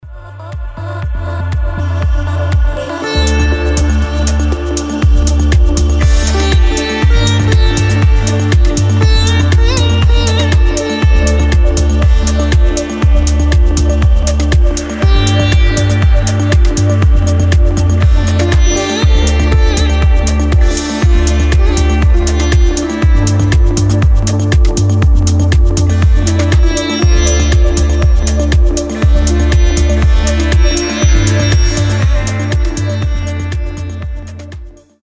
• Качество: 320, Stereo
deep house
без слов
Melodic house